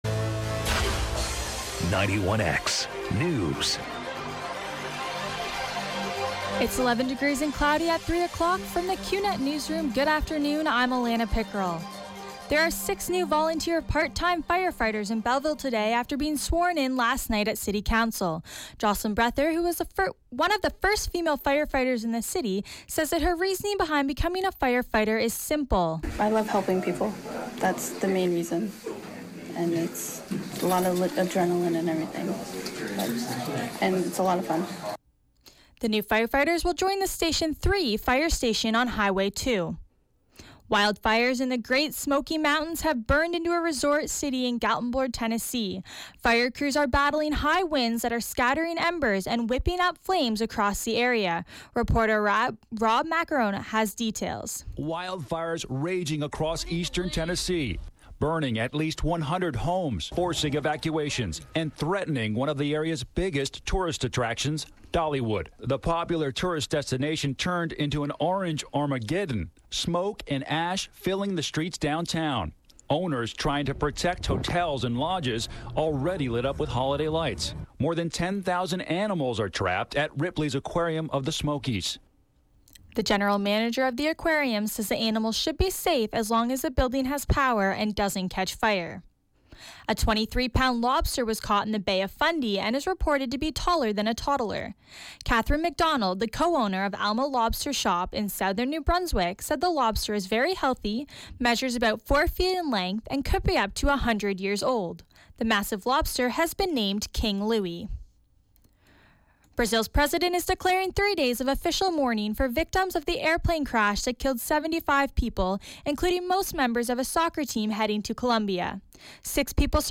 91X FM Newscast – Tuesday, Nov. 29, 2016, 3 p.m.